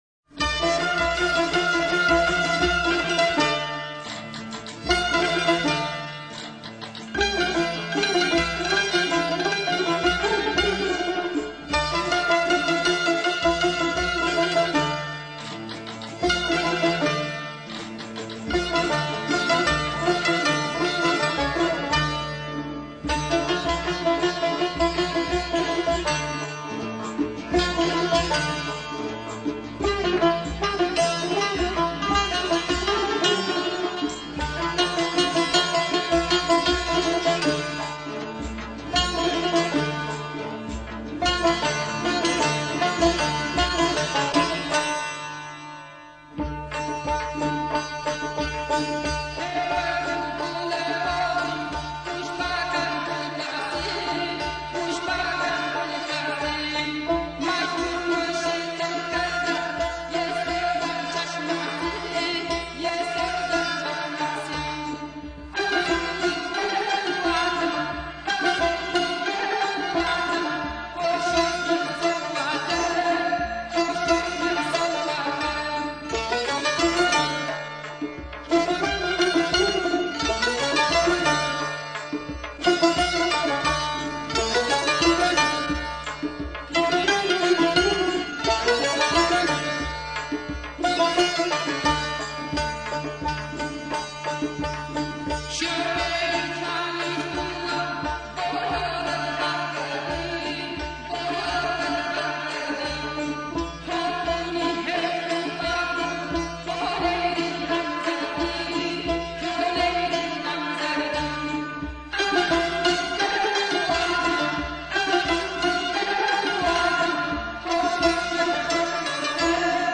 موسیقی لری {استاد رضا سقایی }